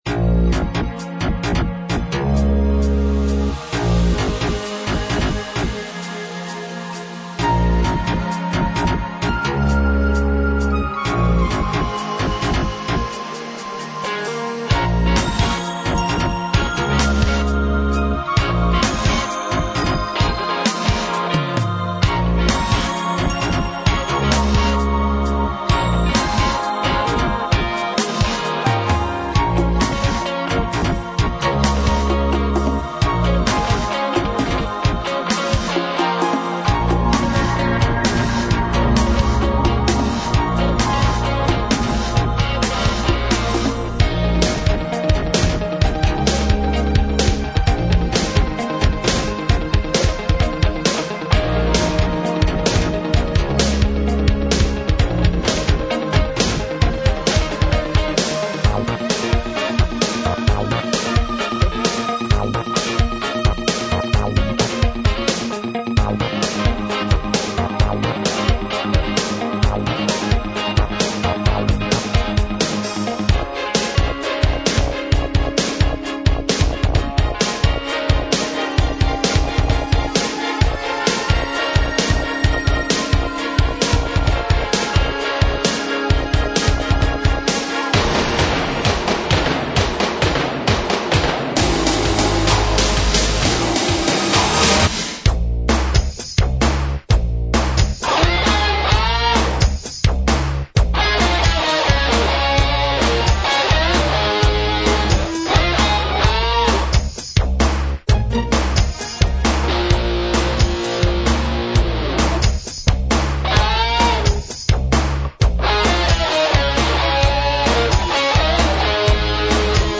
různé druhy hraní